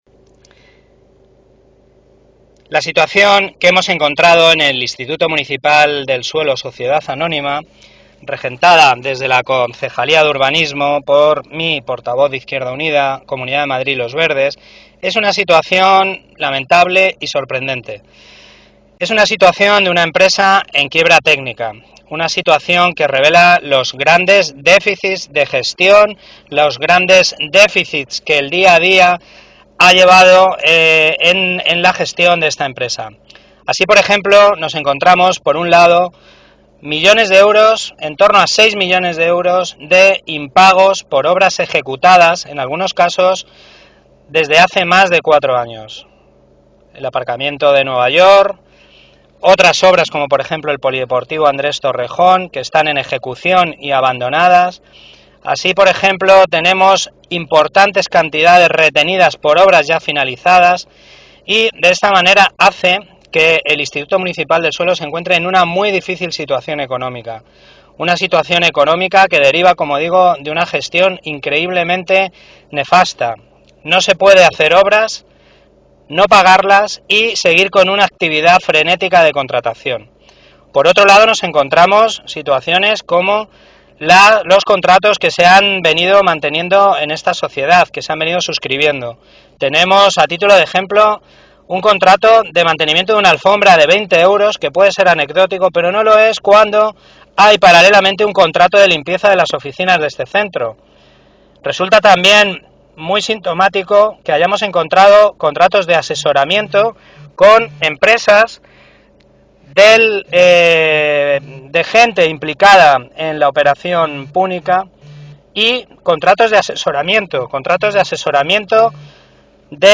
Audio - Eduardo Gutiérrez (Concejal de Urbanismo y Vivienda) Informe del estado actual del IMS